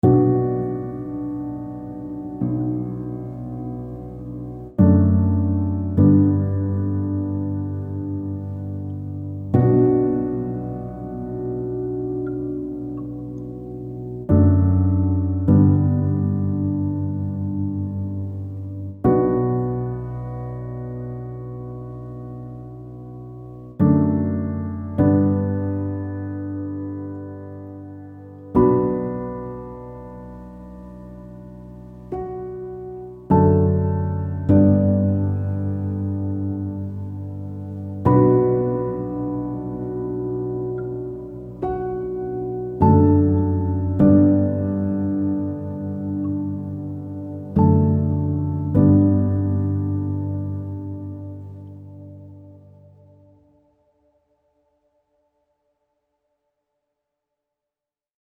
only Piano